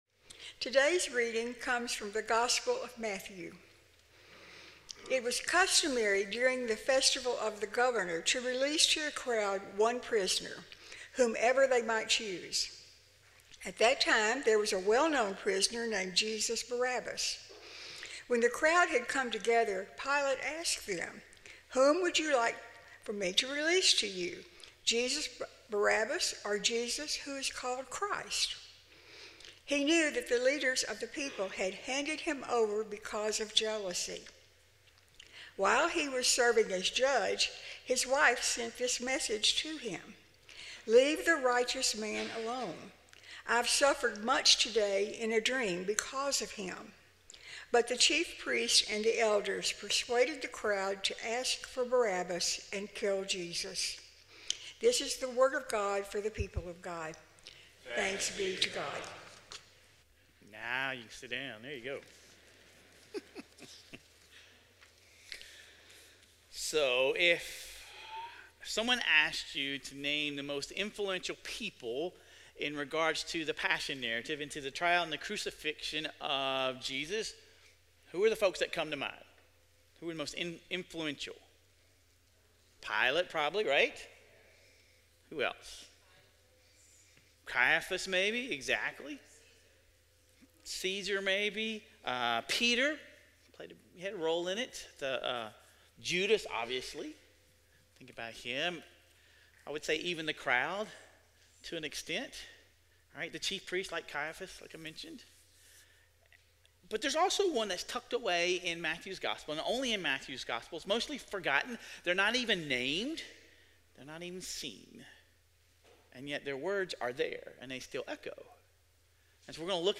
Sermon Reflections: Pilate's wife spoke up despite cultural expectations for women to remain silent in political matters.